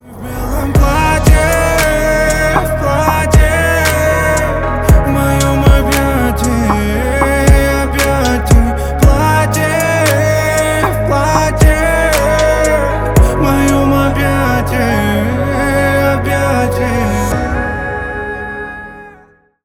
Поп Музыка
тихие # спокойные